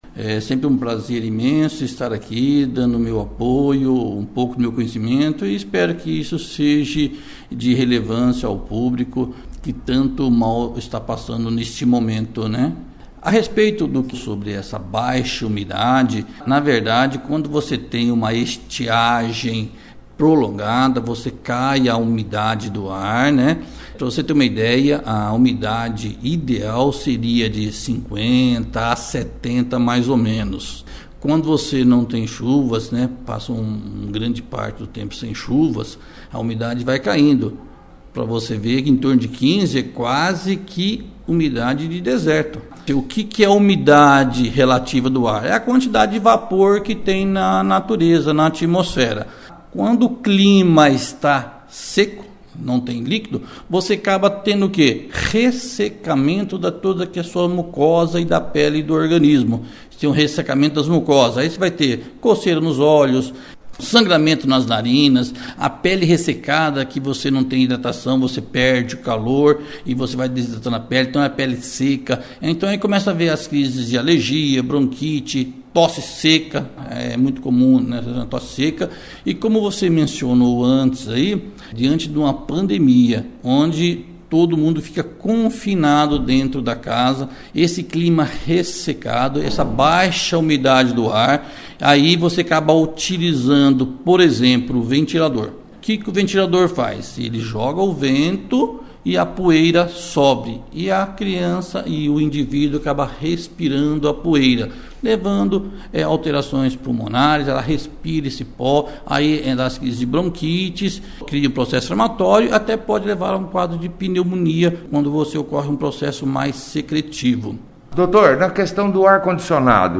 Médico fala do calor recorde e umidade baixa que pedem mais atenção e cuidados com crianças e idosos